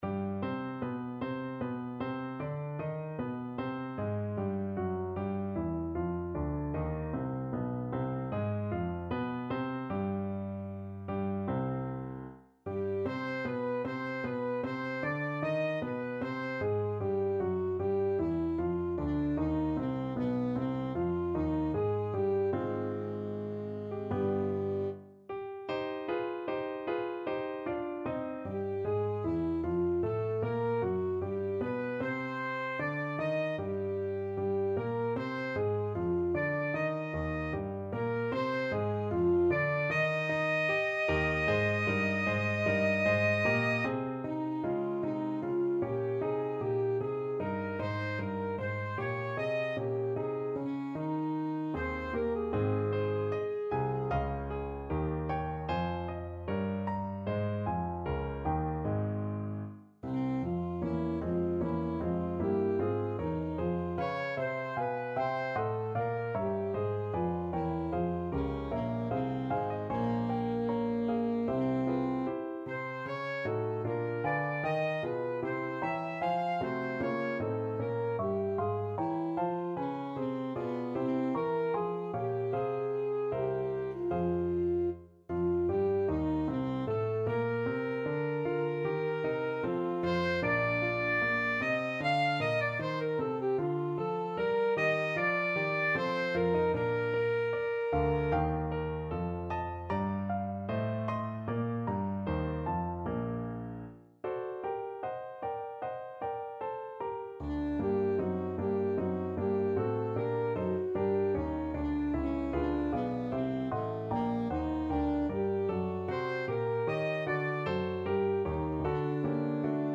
Alto Saxophone
4/4 (View more 4/4 Music)
Larghetto (=76)
Classical (View more Classical Saxophone Music)